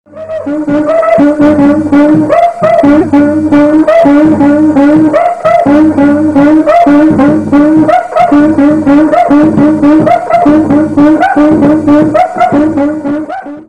CUÍCA cuíca
A cuíca é um instrumento musical com a forma de um barril. No seu interior é presa uma varinha que, atritada, produz um som característico.
cuica.mp3